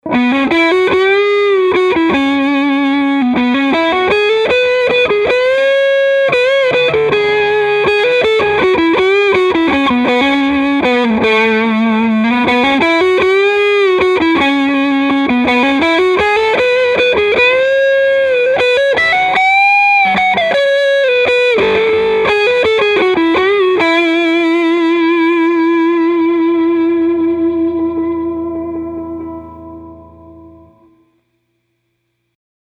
Dirty – Neck Pickup
Clean clips were played in the Clean channel of my Fender Hot Rod Deluxe, while the dirty clips were played in the Drive channel.
dirty-rhythm.mp3